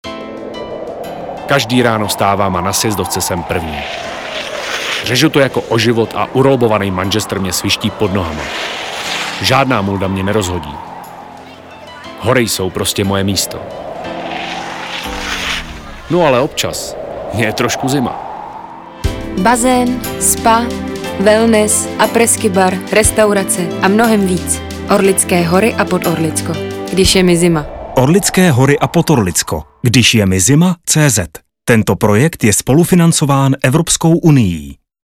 Během února proběhla již druhá vlna audio kampaně na rádiích Expess, Černá hora a Kiss Hády.
Rádiospot Když je mi zima!